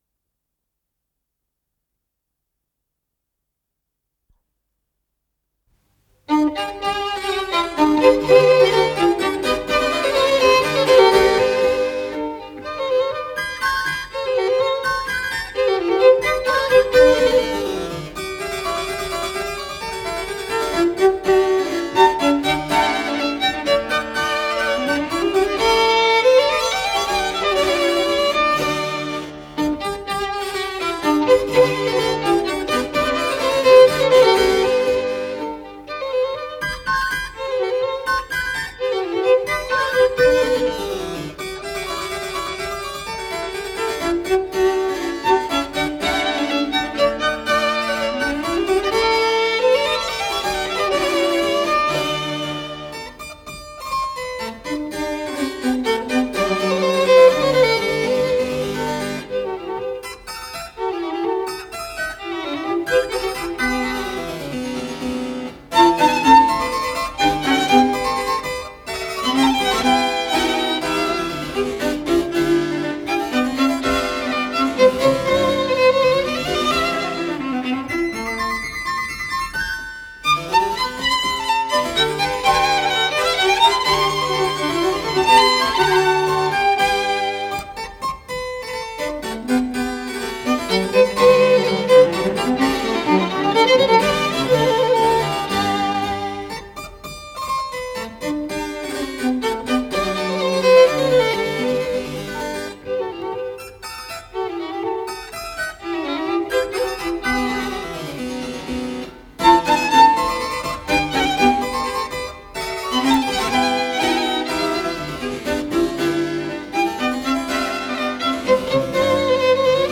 Название передачиКонцерт №2 для клавесина, скрипки и виолы да гамба
клавесин
скрипка
партия виолы да гамба на виолончели